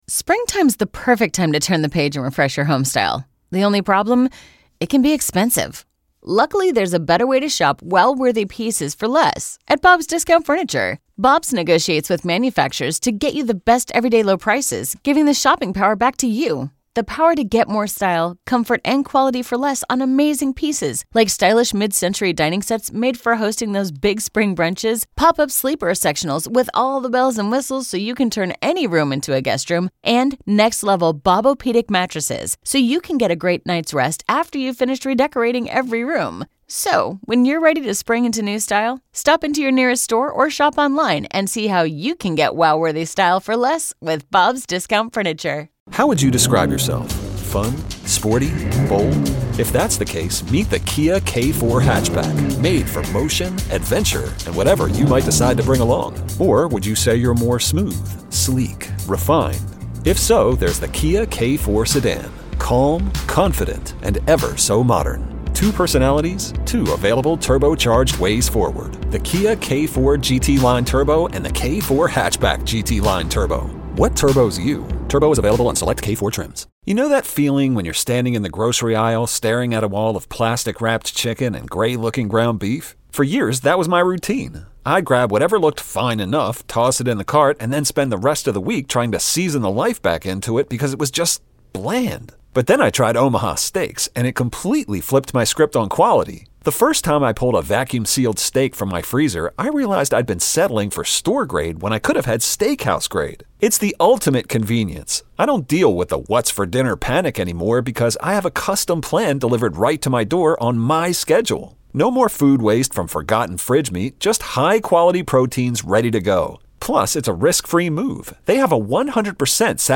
We wrap up our 50 People to Know series with a conversation with legendary newsman Charles Osgood - one of the original WCBS News Anchors.